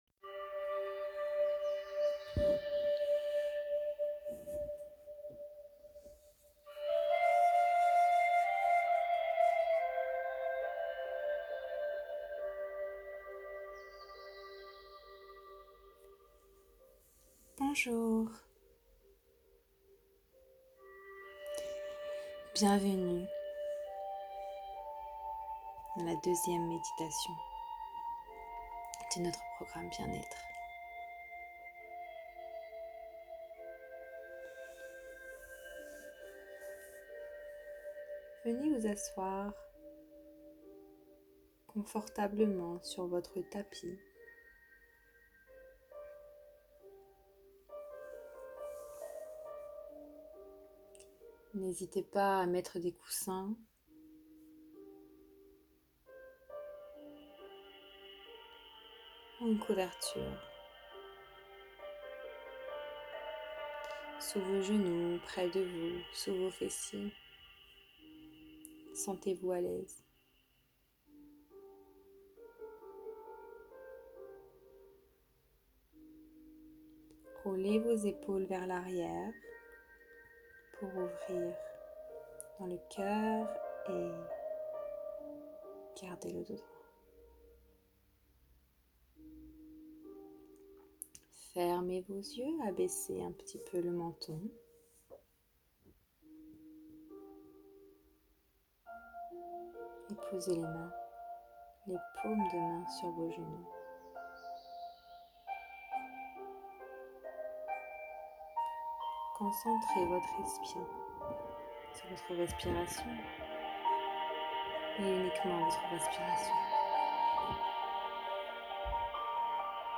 POURSUIS AVEC UNE MEDITATION Parfois la seule chose dont on a besoin est moment calme, un moment à nous loin des distractions extérieures.